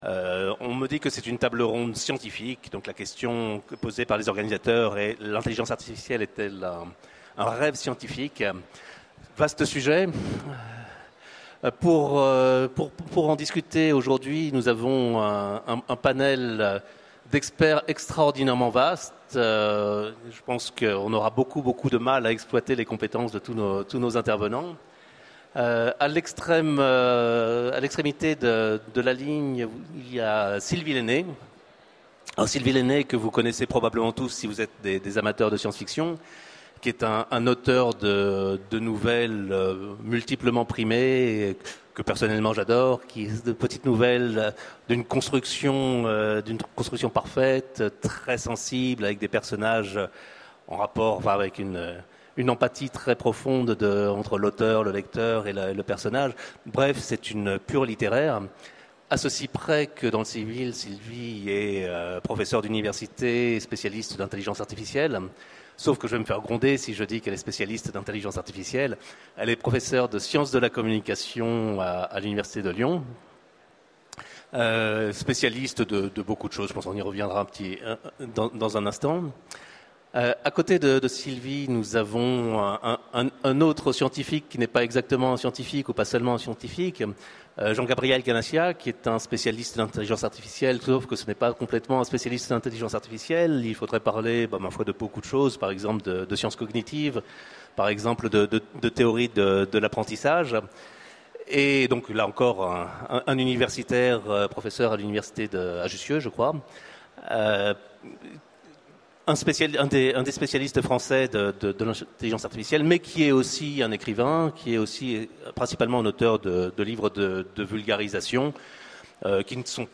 Utopiales 12 : Conférence L’intelligence artificielle est-elle une rêverie scientifique ?